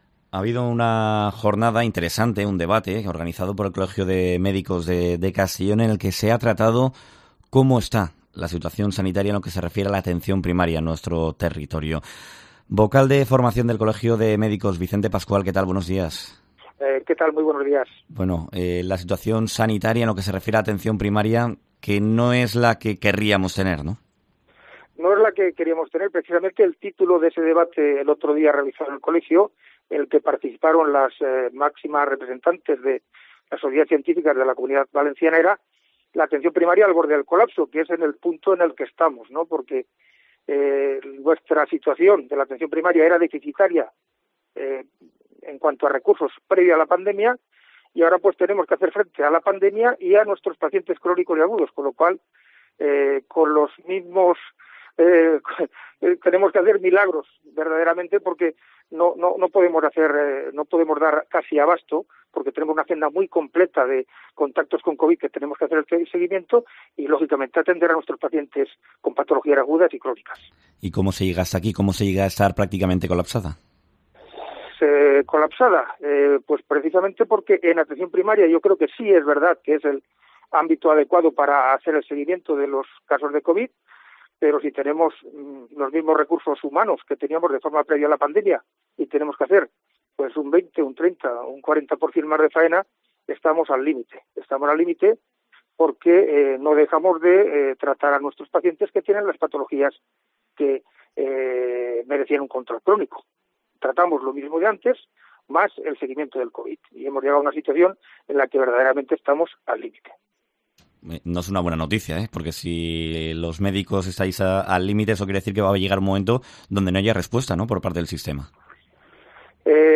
analiza en COPE la situación sanitaria en Castellón